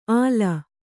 ♪ āla